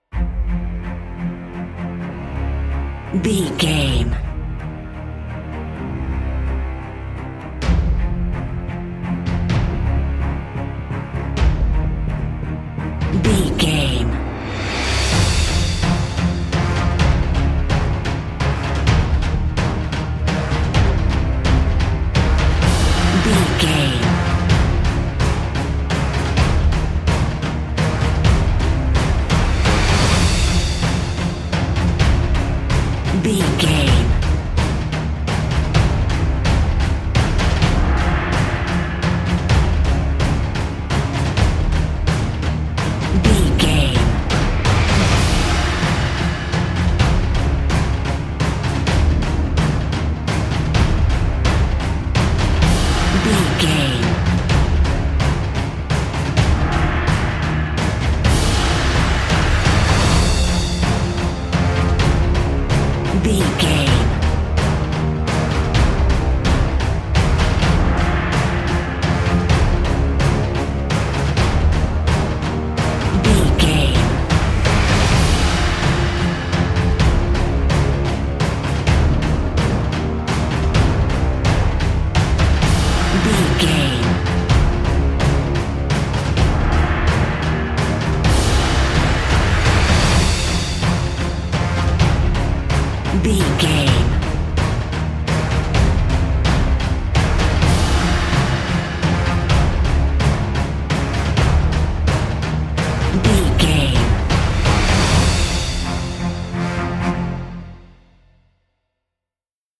Epic / Action
Fast paced
Aeolian/Minor
brass
orchestra
percussion
strings
synthesizers